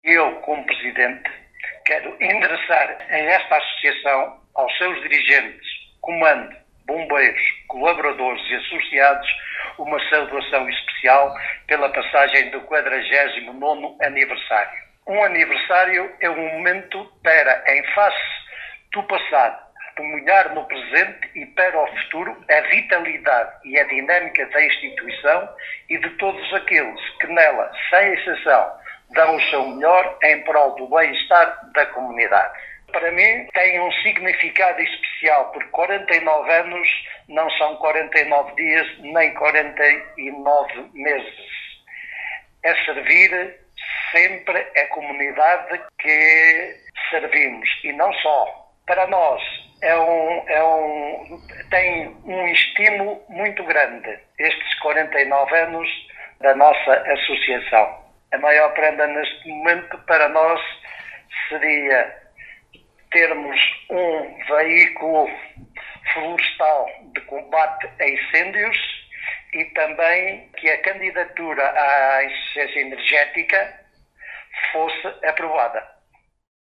em declarações à Alive FM